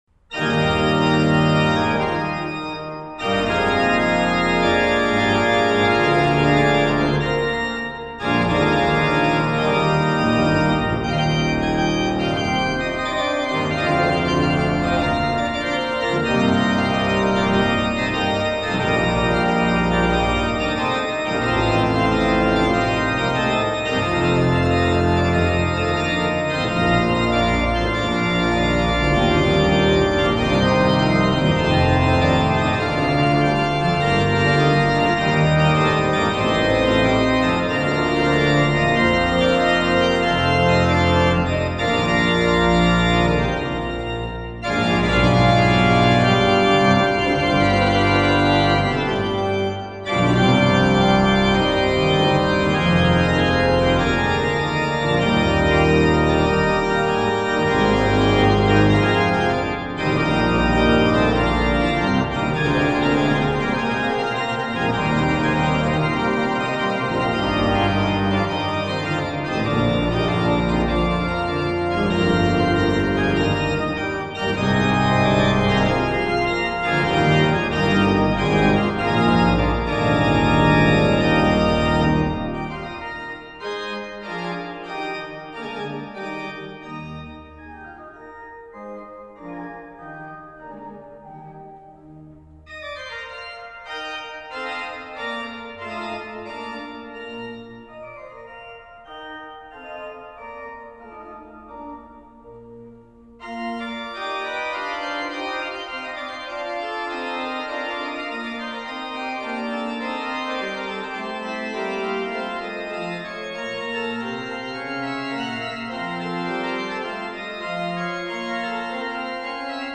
Praeludium für Orgel
von Kirchenmusik Prien Mariä Himmelfahrt | Weihnachten 2020
04-Praeludium-fuer-Orgel.mp3